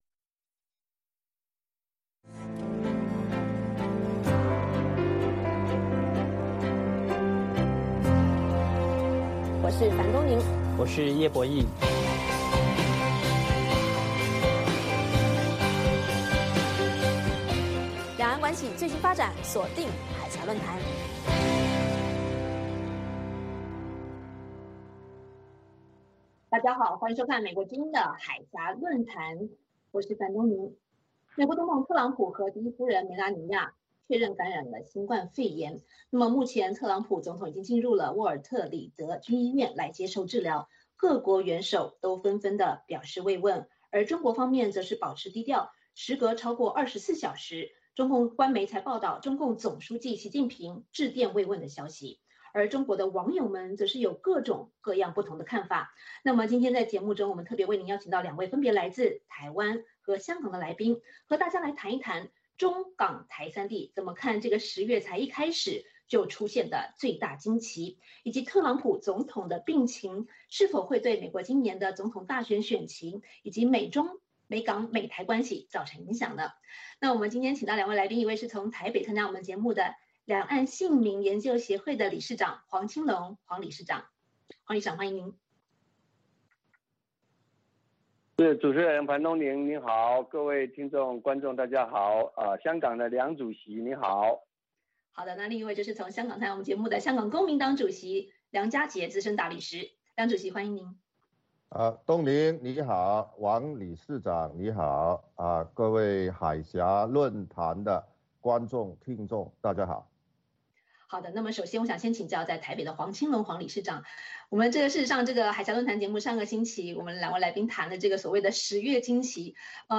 美国之音中文广播于北京时间每周日晚上9-10点播出《海峡论谈》节目(电视、广播同步播出)。《海峡论谈》节目邀请华盛顿和台北专家学者现场讨论政治、经济等各种两岸最新热门话题。